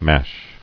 [MASH]